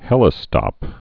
(hĕlĭ-stŏp)